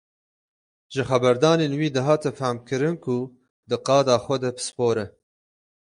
/pɪsˈpoːɾ/